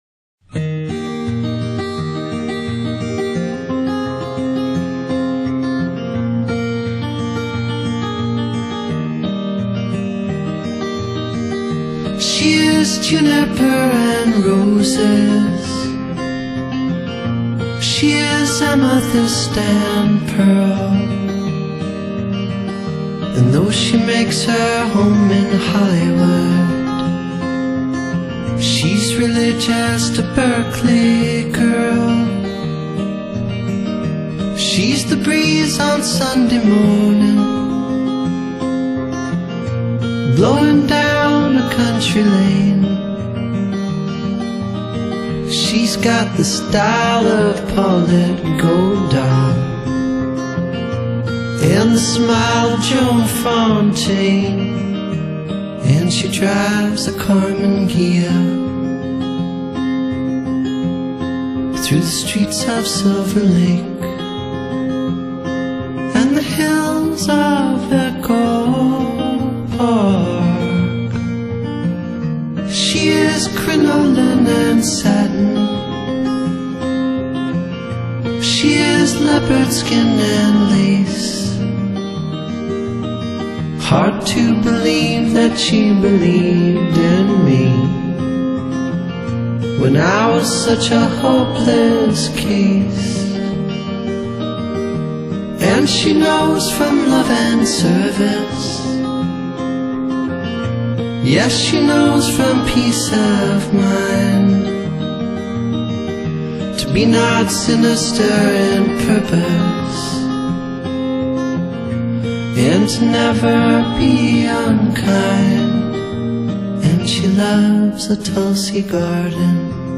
Genre: Rock / Pop / Folk / Word Music